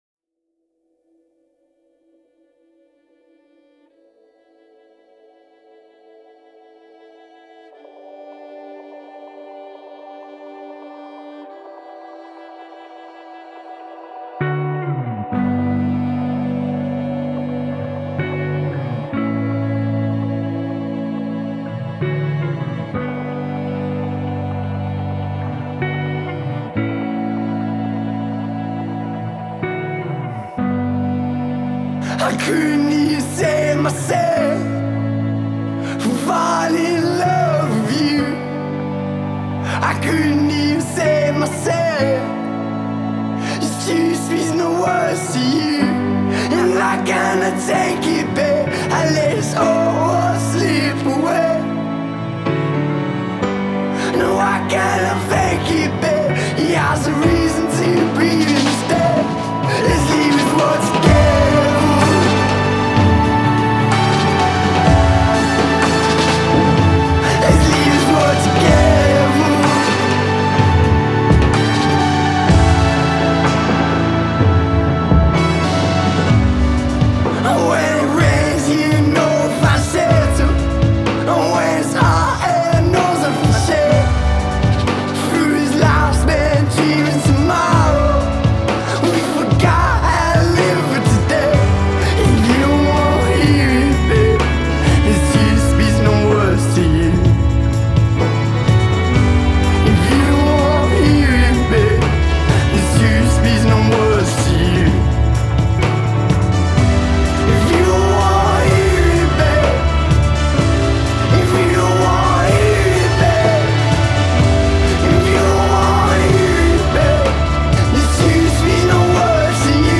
Жанр: Rock.